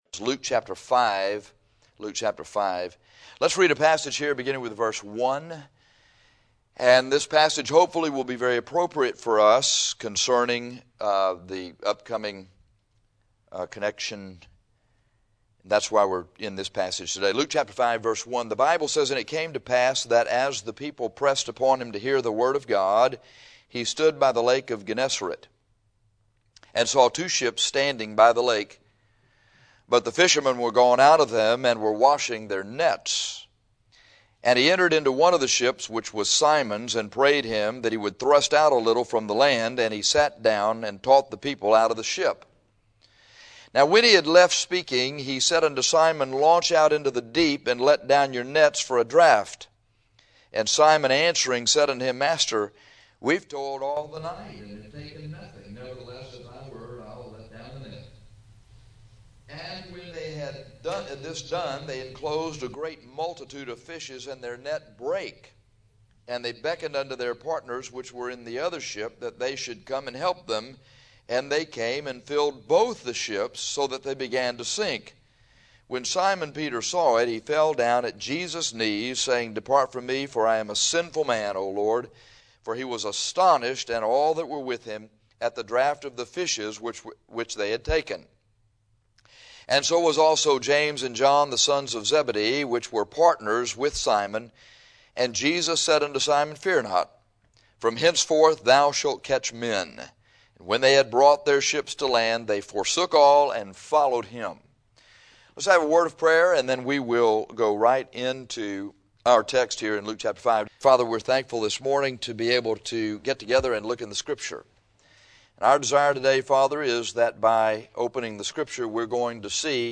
Thou Shalt Catch Men Luke 5:1-11 CLICK TITLE FOR AUDIO- THIS RECORDING WAS ENDED ABRUPTLY.